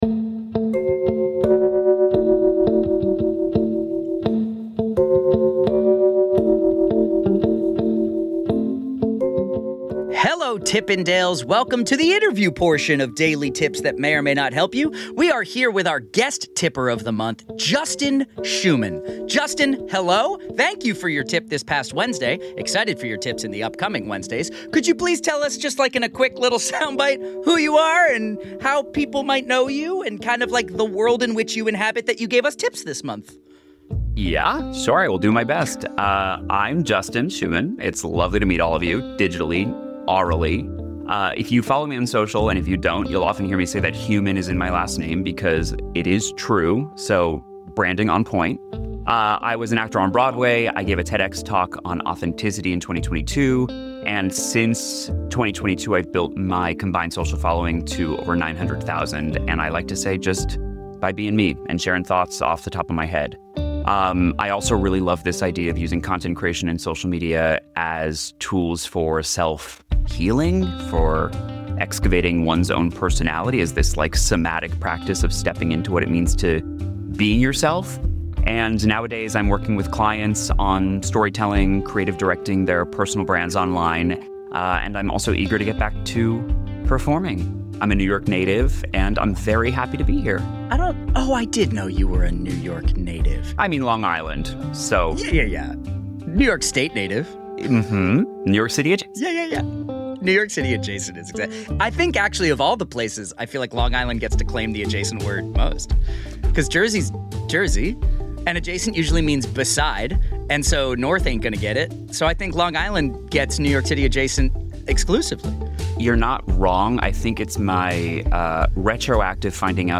In this interview episode of Daily Tips That May or May Not Help You